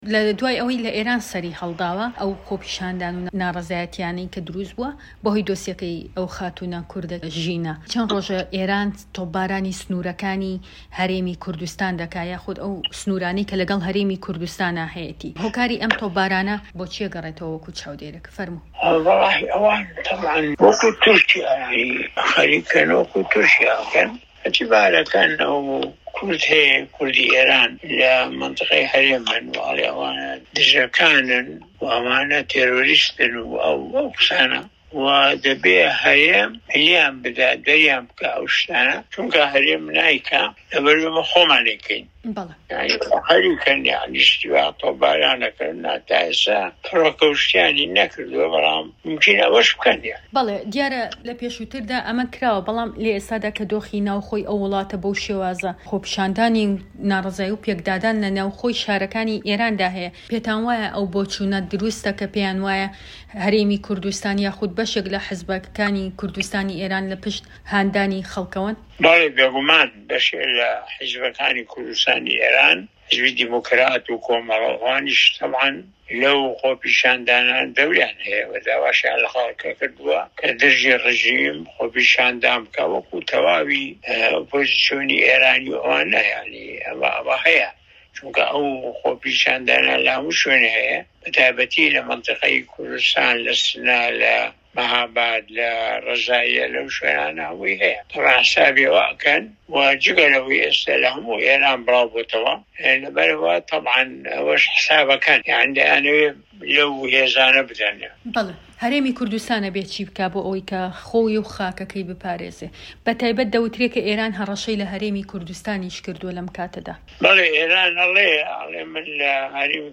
دەقی وتووێژەکەی دکتۆر مەحمود عوسمان